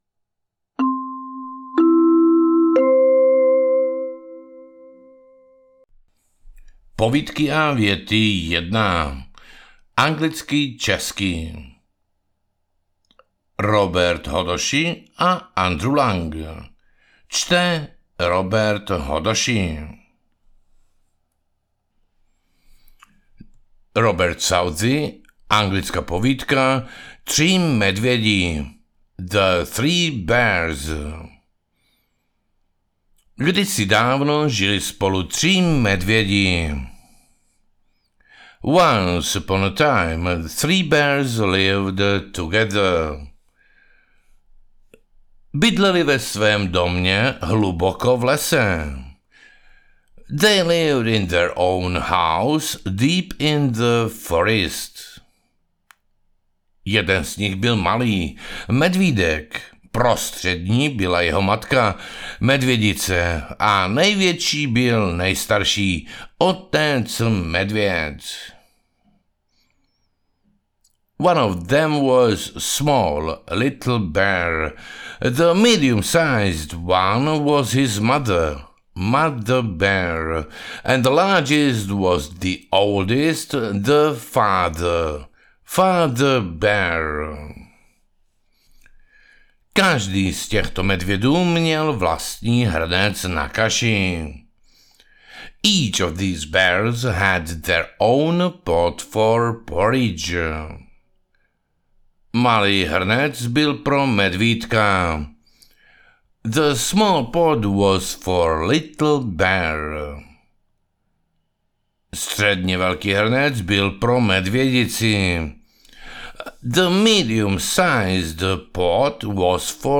Objevte kouzelný svět pohádek v této okouzlující české audioknize pro děti od 3 do 6 let! Sbírka 17 klasických příběhů, jako Tři medvědi, Princezna na hrášku nebo Ošklivé káčátko, ožívá díky živému vyprávění a zvukovým efektům. Každá povídka je doplněna ponaučením, které učí děti hodnotám jako odvaha, laskavost a opatrnost.
AudioKniha ke stažení, 23 x mp3, délka 4 hod. 18 min., velikost 352,7 MB, česky